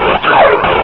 /hl2/sound/npc/combine_soldier/test/near/
player_dead1.ogg